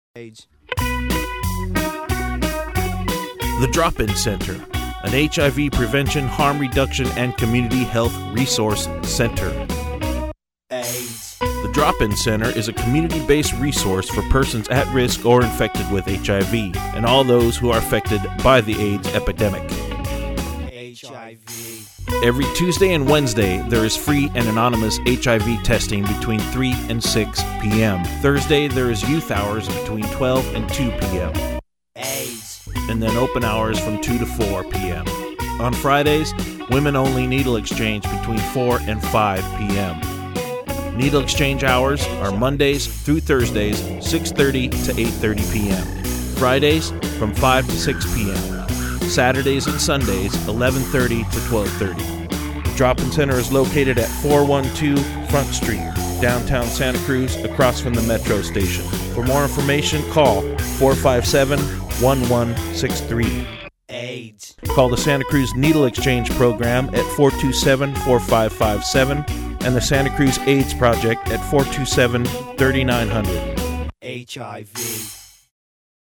Audio PSA produced for Free Radio Santa Cruz 101fm
Music by Ween.